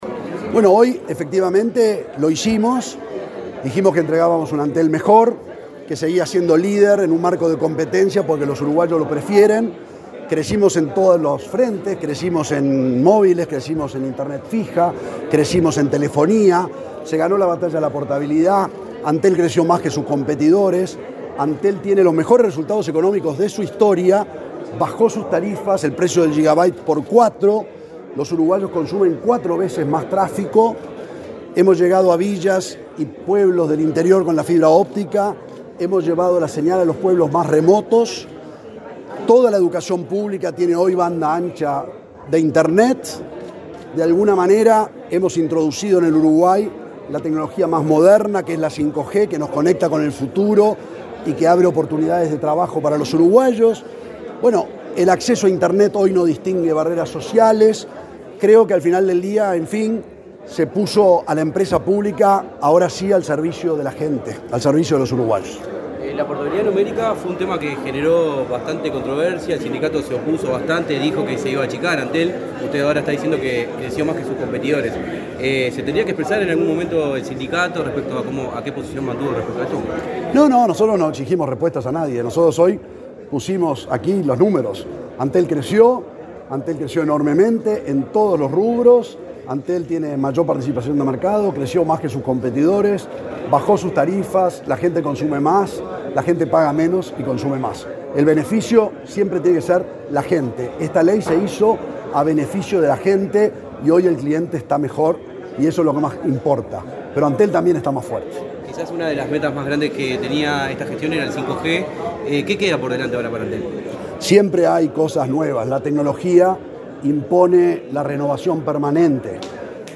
Declaraciones del presidente de Antel a la prensa
Declaraciones del presidente de Antel a la prensa 12/10/2023 Compartir Facebook X Copiar enlace WhatsApp LinkedIn El presidente de Antel, Gabriel Gurméndez, disertó, este jueves 12 en Montevideo, en un almuerzo de trabajo de la Asociación de Dirigentes de Marketing. Luego, dialogó con la prensa.